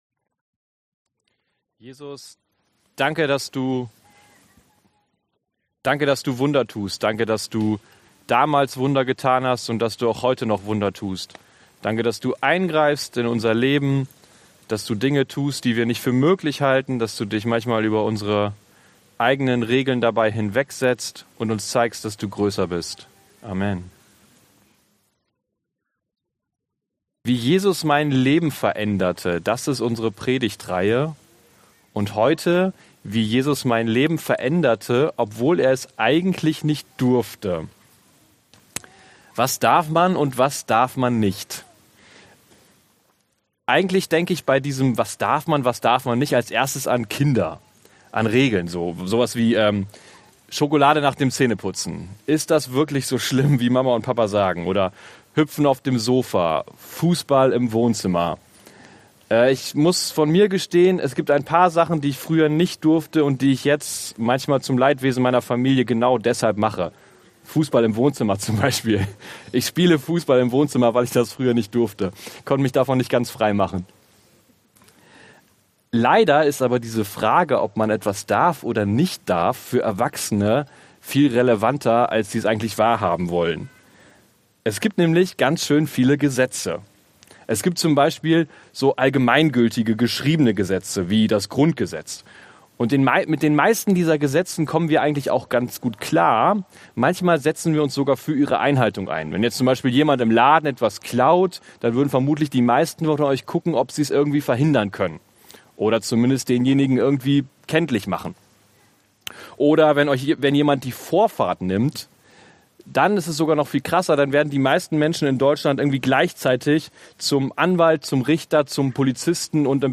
wie Jesus mein Leben veränderte Passage: Lukas 6, 6ff Dienstart: Predigt Darf ich das?